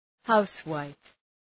{‘haʋswaıf}
housewife.mp3